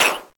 yellowPop.ogg